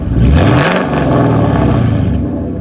V8 reving
v8-reving.mp3